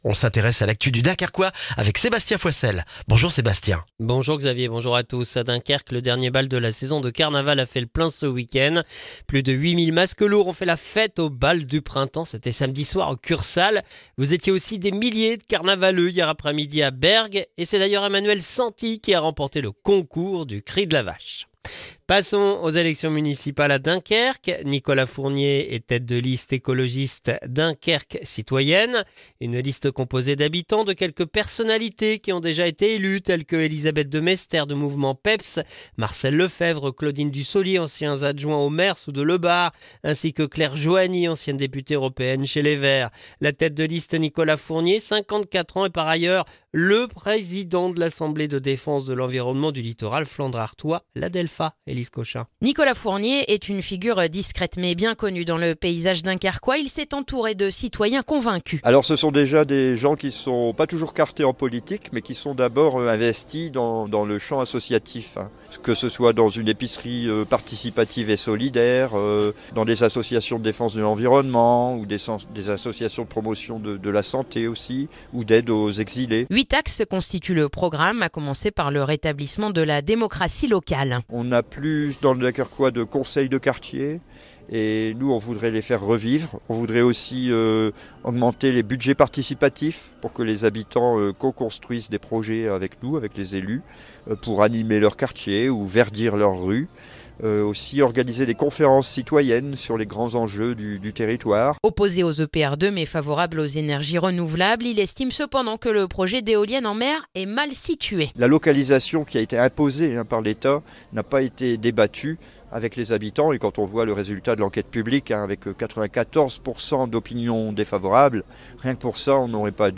Le journal du lundi 9 mars dans le dunkerquois